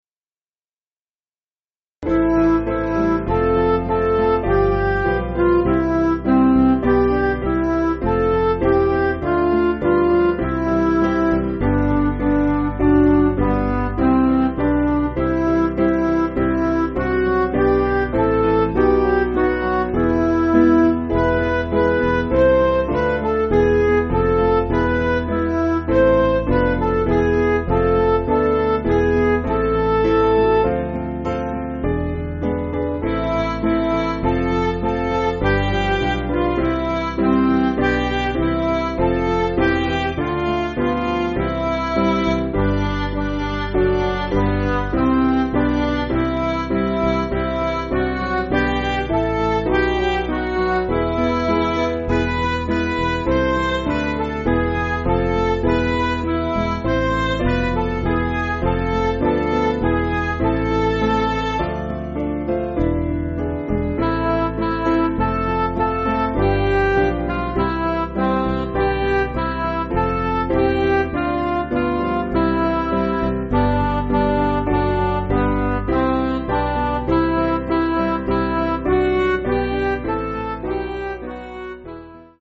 Piano & Instrumental
(CM)   4/Am